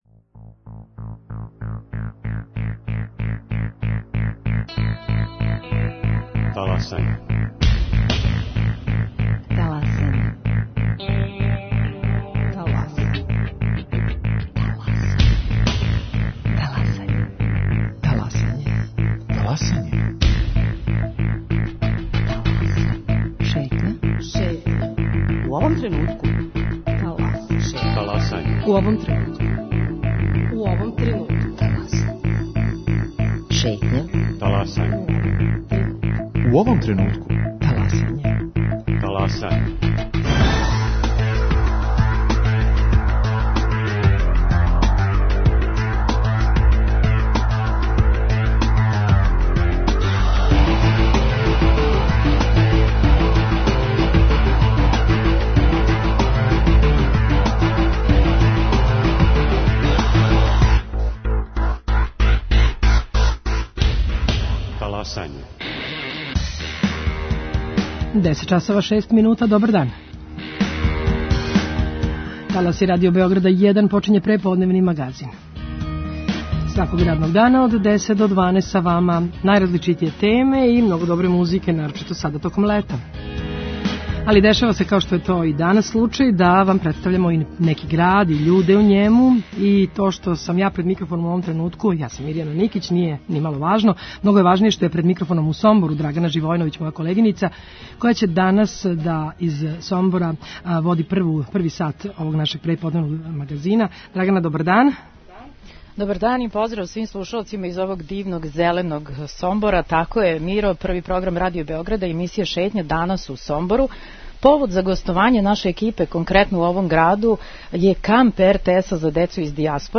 Шетњу емитујемо из Сомбора.